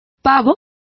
Complete with pronunciation of the translation of turkeys.